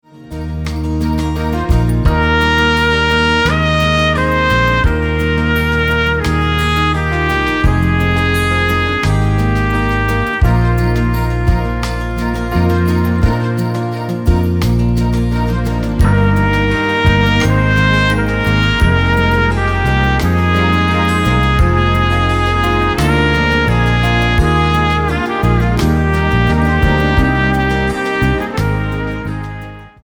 Instrumental-CD